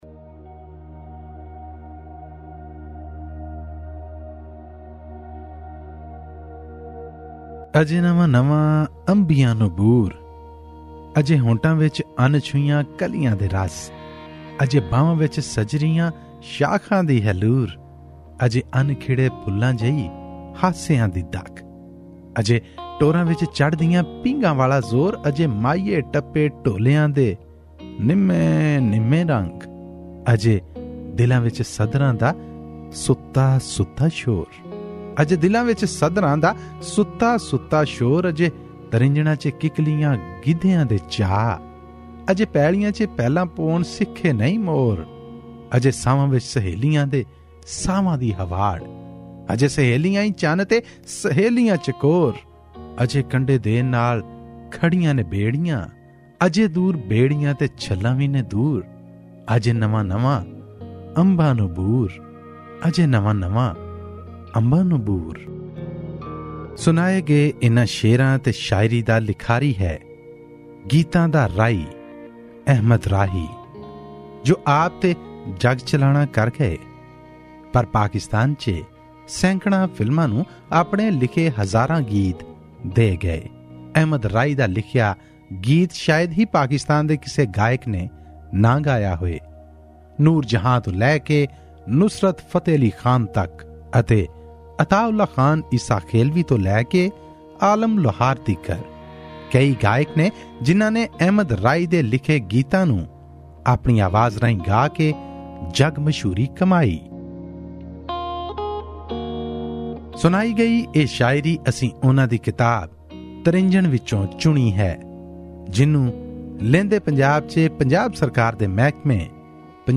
Pakistani Punjabi poetry book review: 'Trinjna' by Ahmed Rahi